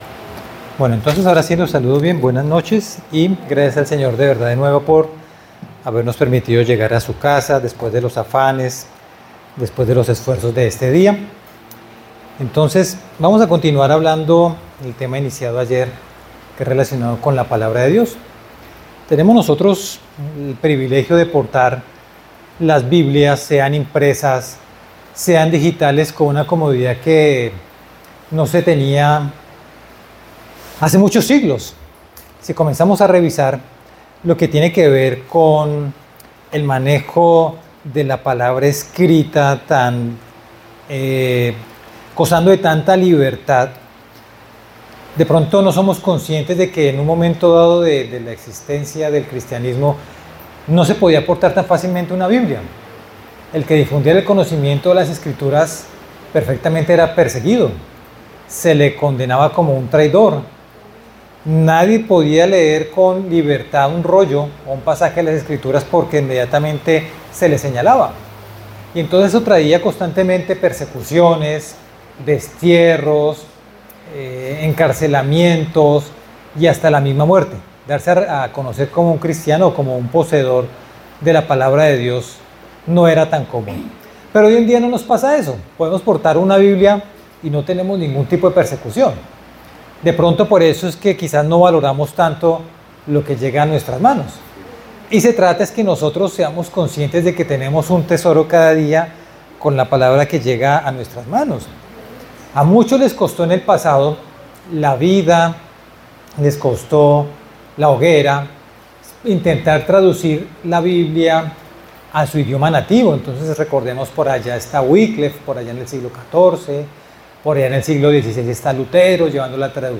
Un excelente mensaje espiritual acerca de la importancia de la Santa Biblia y como debemos hacer de ella nuestra principal fuente de estudio diario.